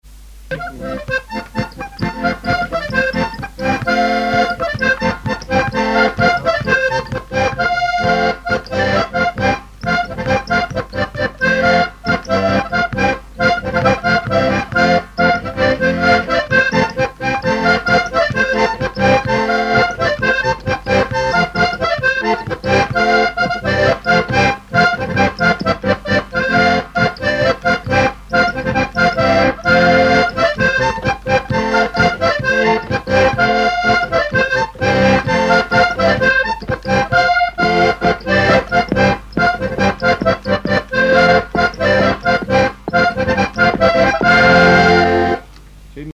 Saint-Urbain
danse : branle : courante, maraîchine
instrumentaux à l'accordéon diatonique
Pièce musicale inédite